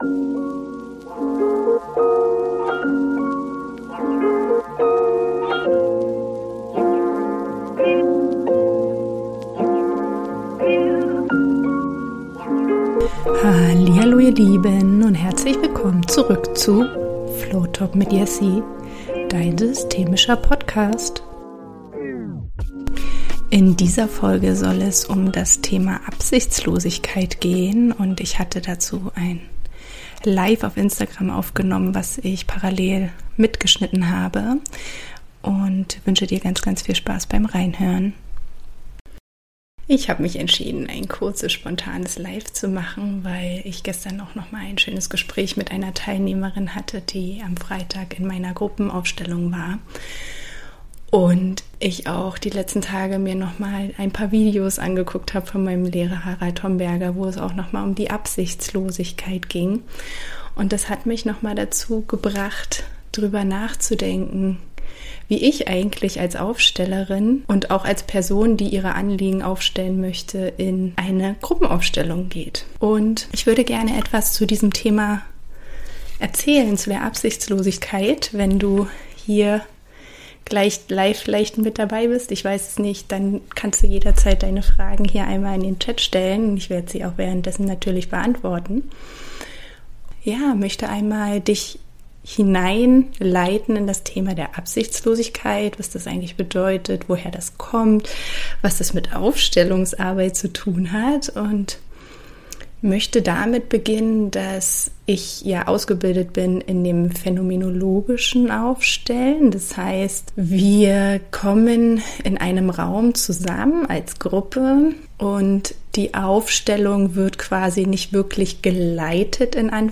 Hallo ihr Lieben, dies ist ein Mitschnitt aus einem spontanem Live